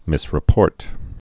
(mĭsrĭ-pôrt)